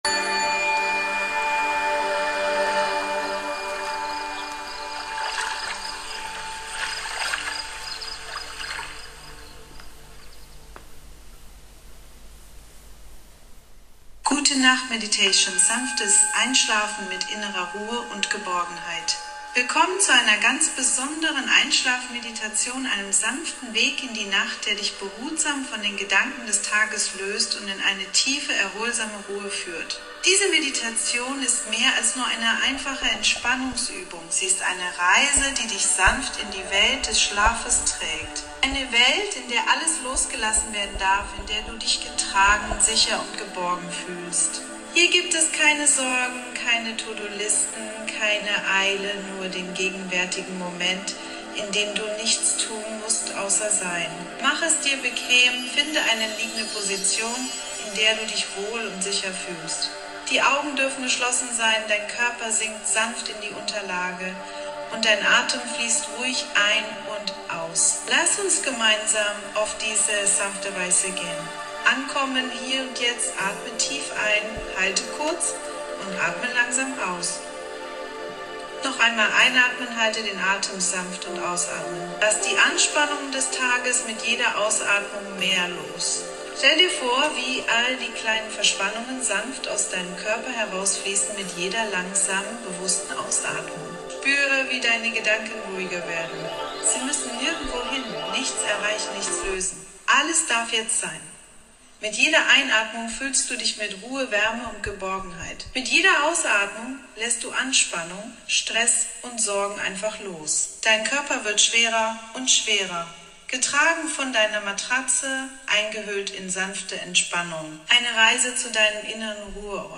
Gute Nacht Meditation – Sanftes Einschlafen mit innerer Ruhe &